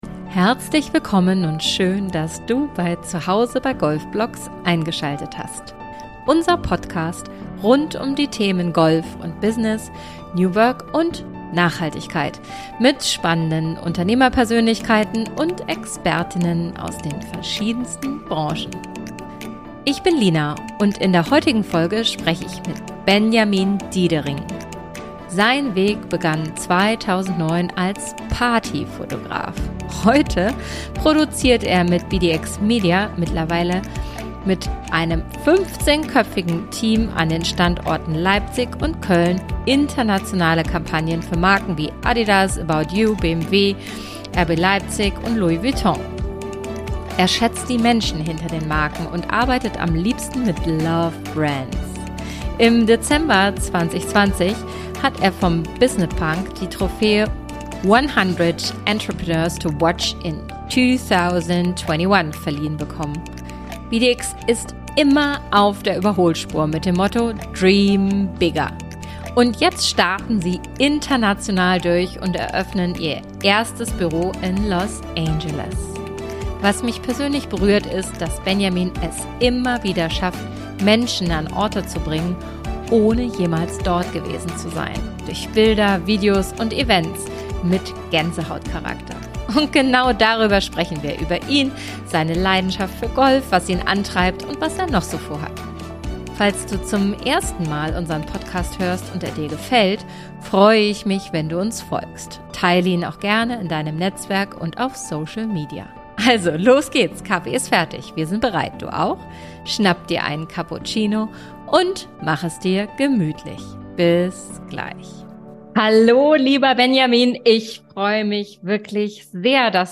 Aus Los Angeles schaltet er sich in unsere Podcast-Aufnahme.